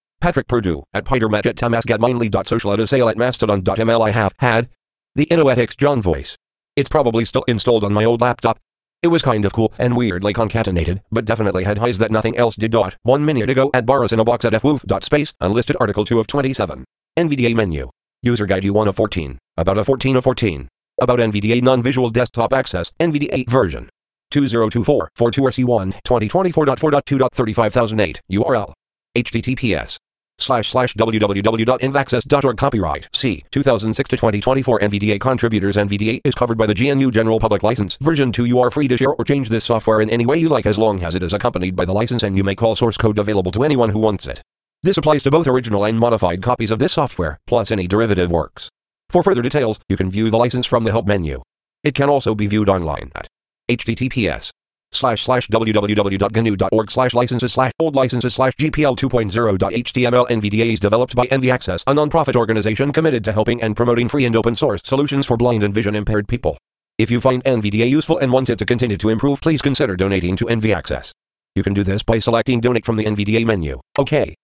I stitched together an audio file showing you how bad it is at ignoring the setting of -1 as the output.
I updated this to add a little more at the end and show how Mist World treats audio output switching properly, that I now know is not proper.
Listen for the sharpness of S's and other consonants.